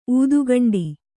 ♪ ūdugaṇḍi